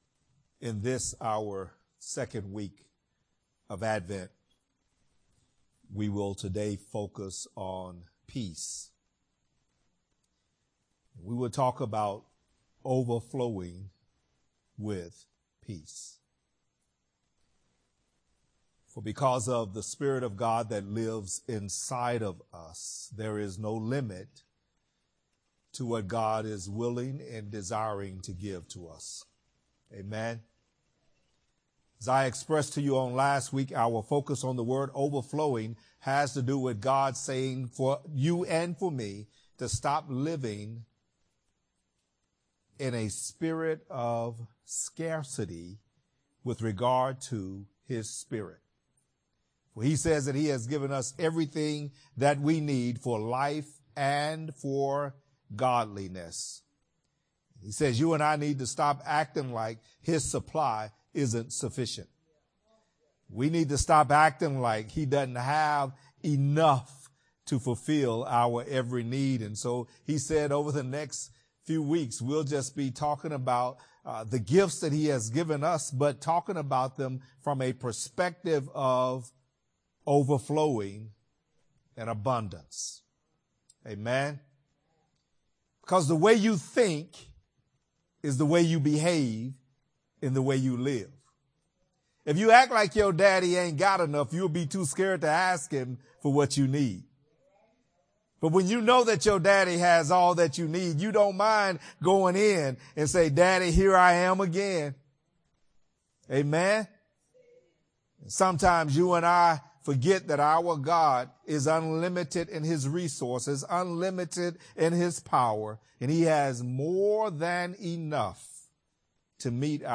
Sermons | New Joy Fellowship Ministry